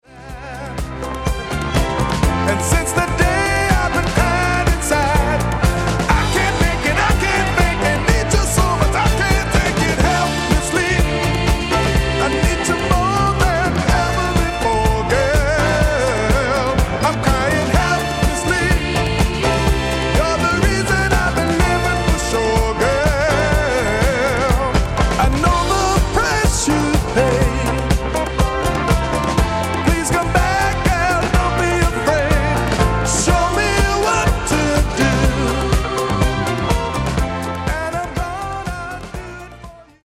Disco Funk e Dance Clssics degli anni 70 e 80.